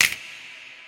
Southside Percussion (4).wav